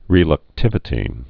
(rĕlək-tĭvĭ-tē)